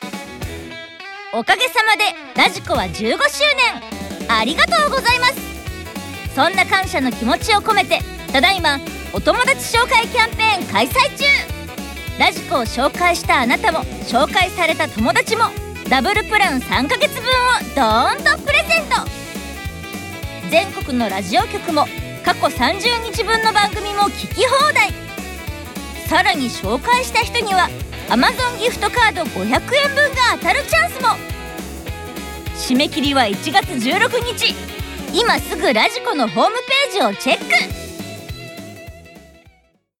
radiko15周年記念「お友達ご紹介キャンペーン」CM 20秒/40秒